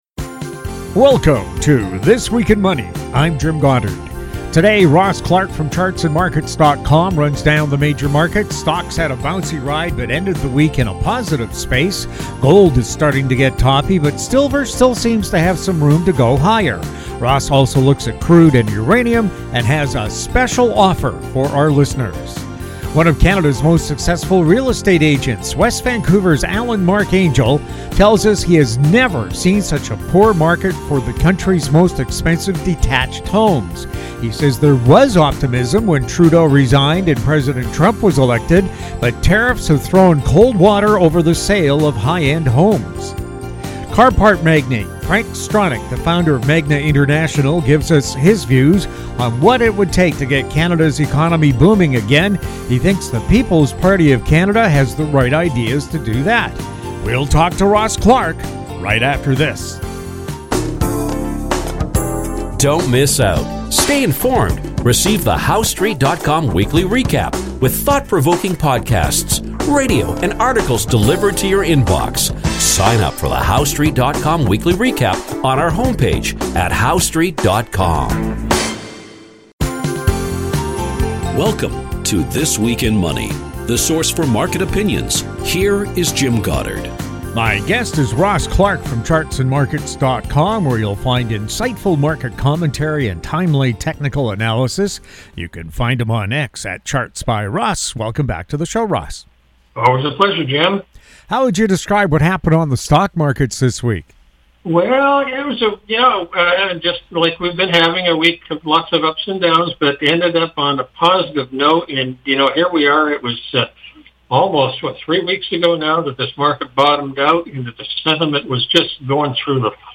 New shows air Saturdays on Internet Radio.